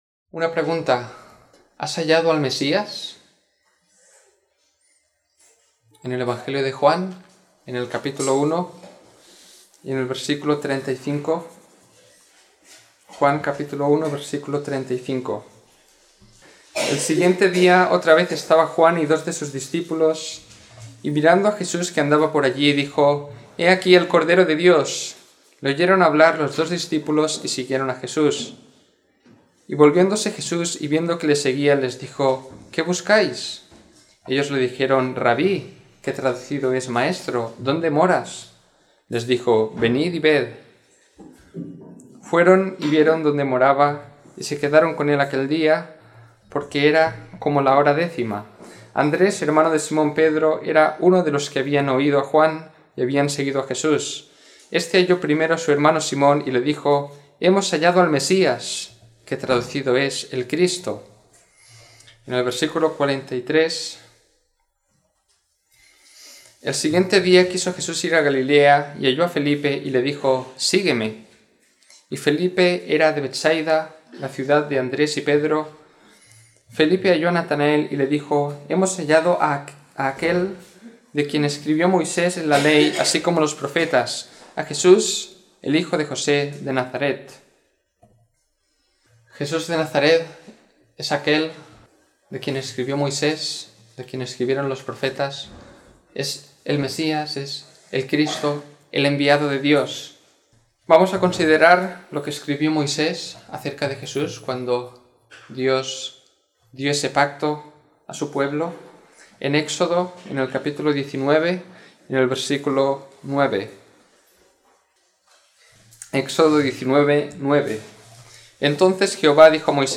Domingo por la Mañana